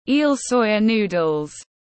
Miến lươn tiếng anh gọi là eel soya noodles, phiên âm tiếng anh đọc là /iːl ˈsɔɪ.ə nuː.dəl/
Eel soya noodles /iːl ˈsɔɪ.ə nuː.dəl/